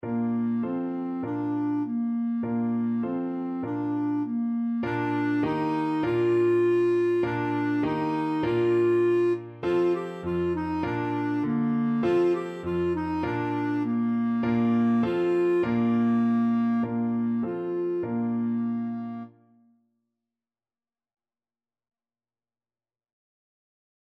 Traditional Trad. Frere Jacques Clarinet version
Clarinet
Bb major (Sounding Pitch) C major (Clarinet in Bb) (View more Bb major Music for Clarinet )
4/4 (View more 4/4 Music)
Bb4-G5